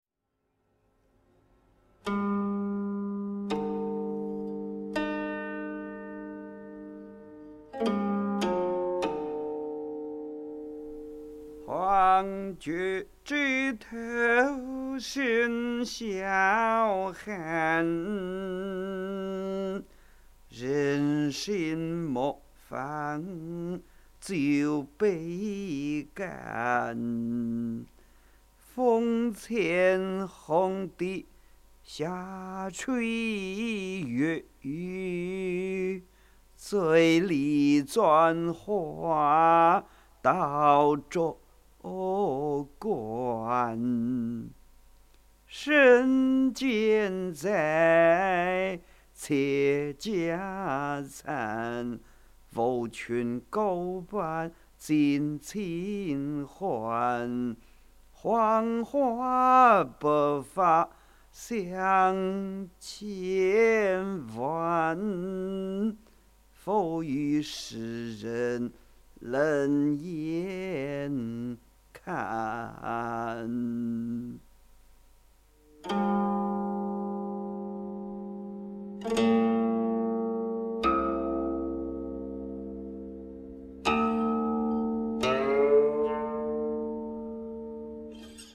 吟唱